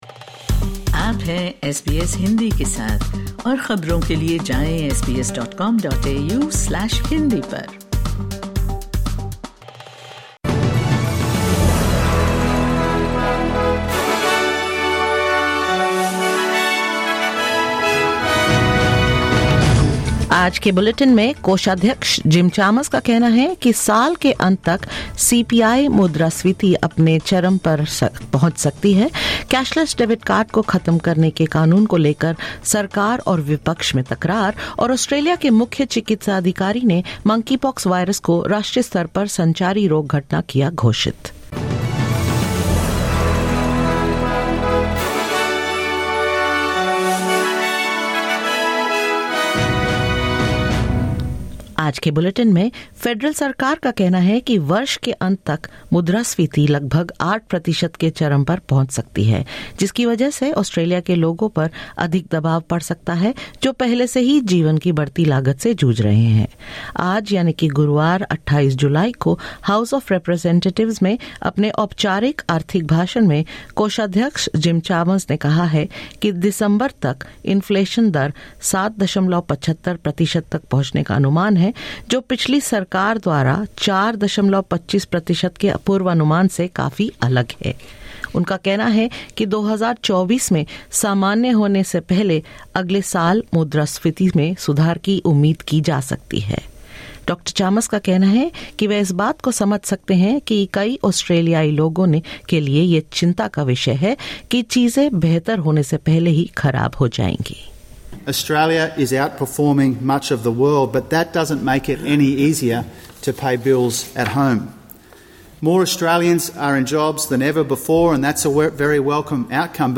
SBS Hindi News 28 July 2022: Australian Inflation rate tipped to reach 7.75 per cent this year | SBS Hindi